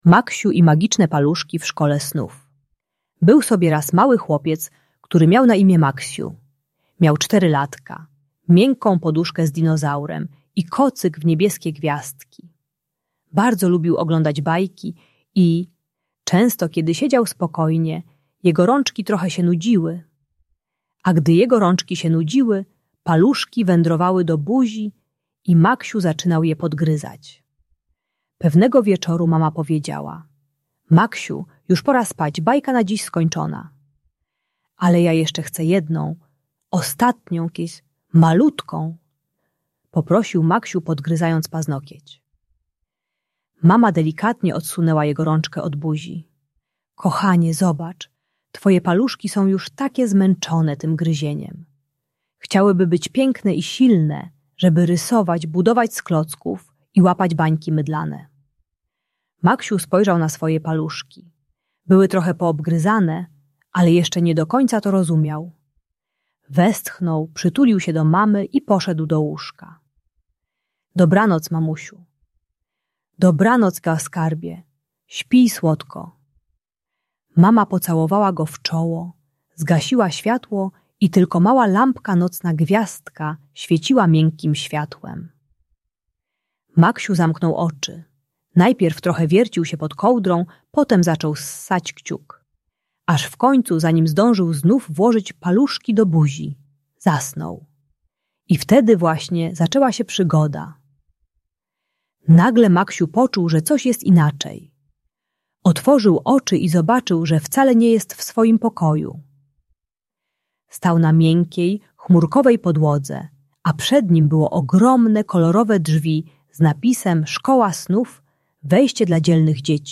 Maksiu i Magiczne Paluszki - Niepokojące zachowania | Audiobajka